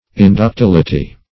Inductility \In`duc*til"i*ty\, n. The quality or state of being inductile.